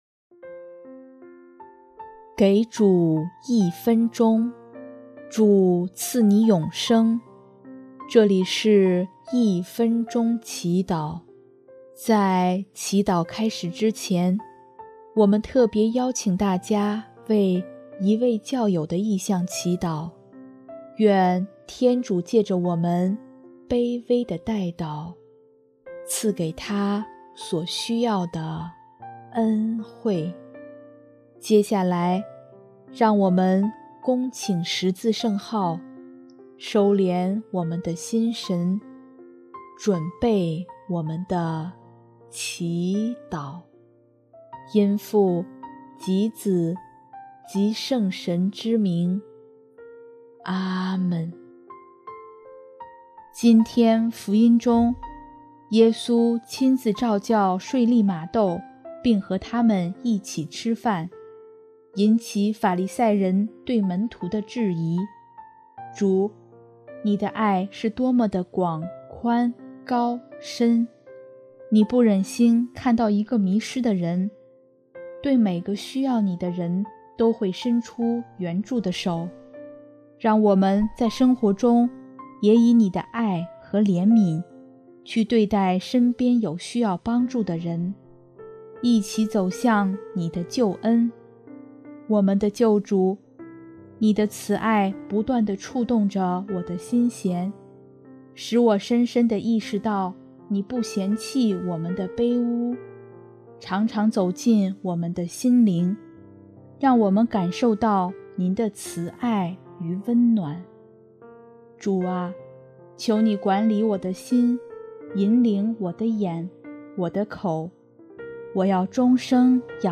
【一分钟祈祷】|7月4日 不是健康的人需要医生，而是有病的人